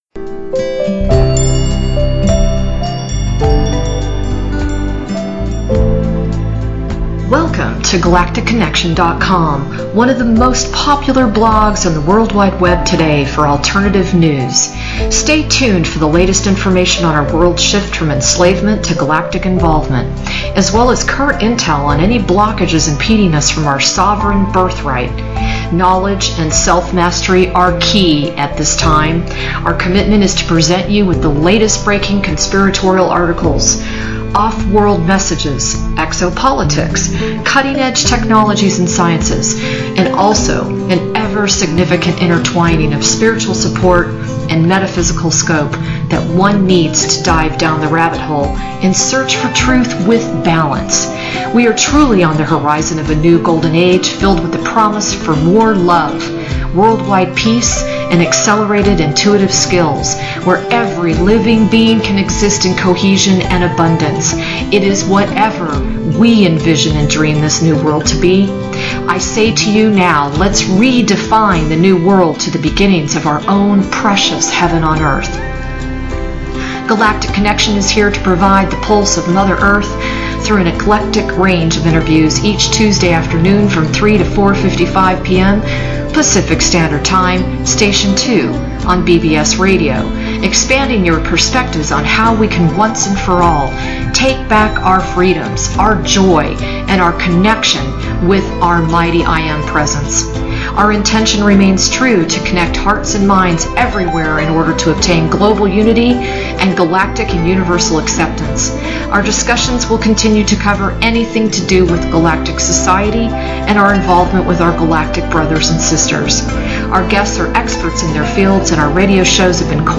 Talk Show Episode, Audio Podcast, Galactic Connection: The Divine Right of Financial Sovereignty!